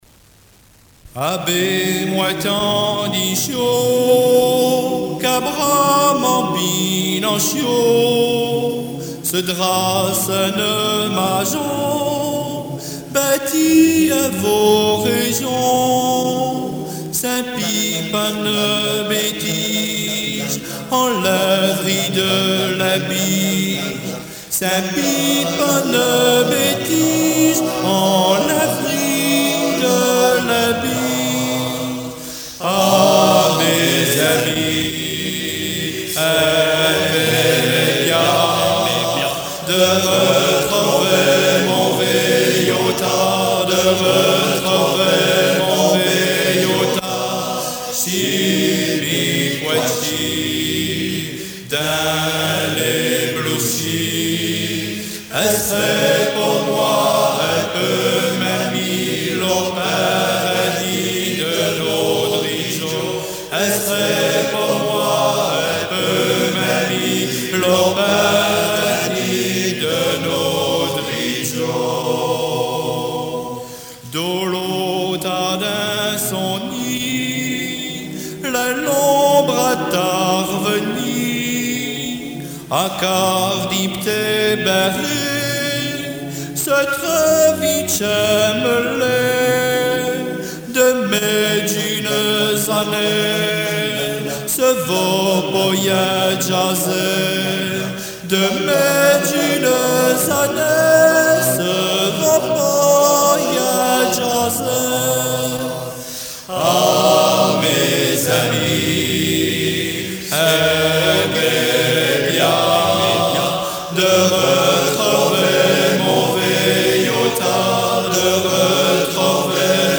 ---- Chorale de l’Amicale des Patoisants Vadais